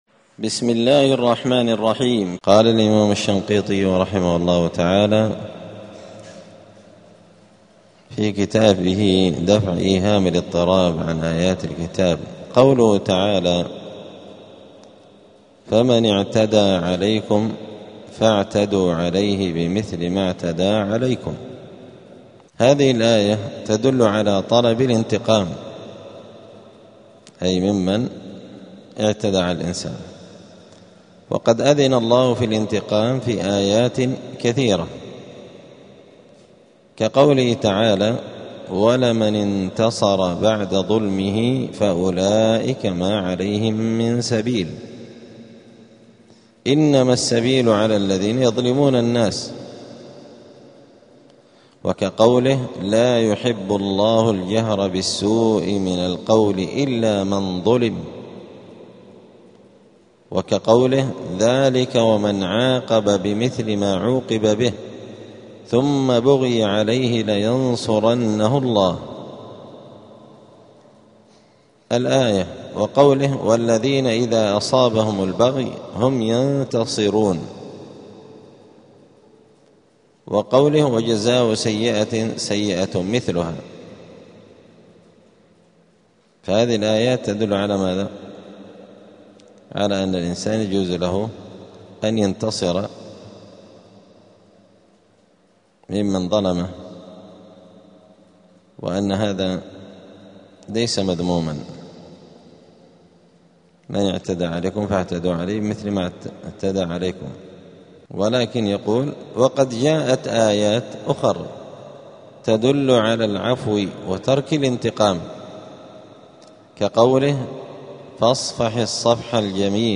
*الدرس الثاني عشر (12) {سورة البقرة}.*